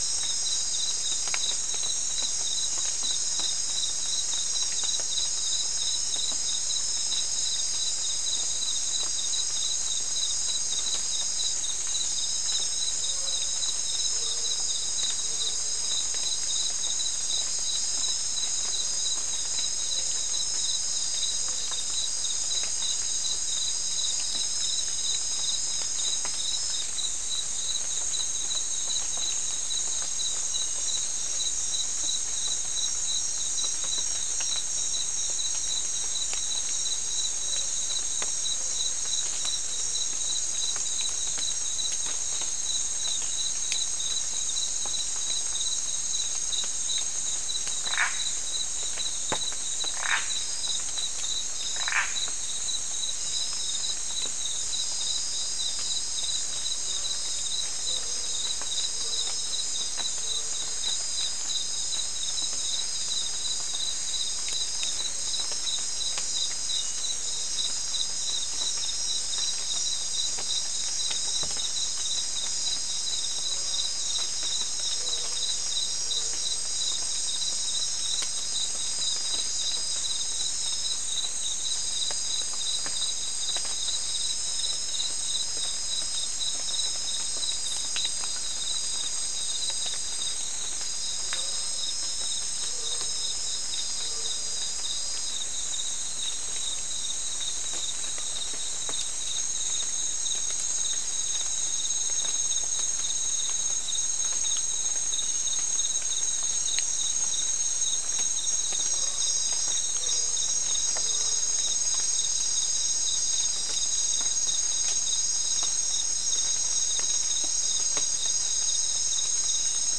Non-specimen recording: Soundscape Recording Location: South America: Guyana: Mill Site: 3
Recorder: SM3